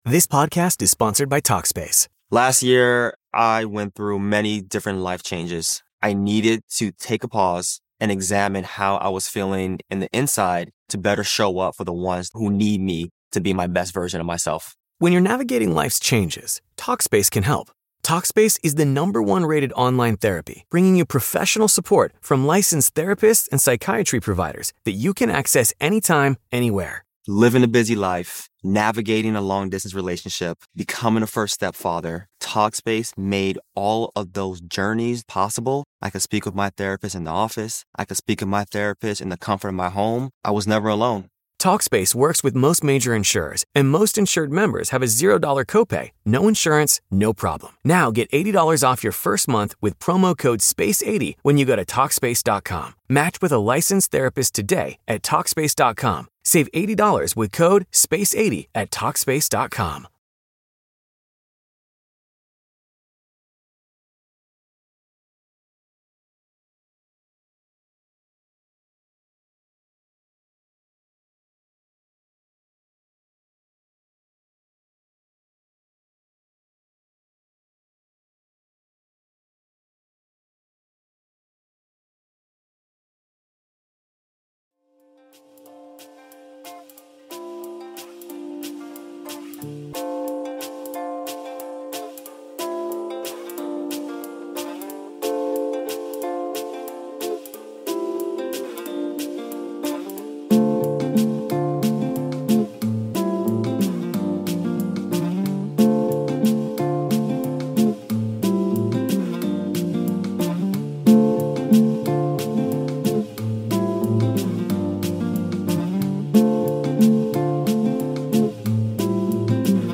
Live Q&A - 83 - Ask Me Anything!
Welcome to the live Q&A, where it is never a dull moment!